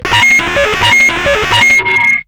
RADIOFX  1-L.wav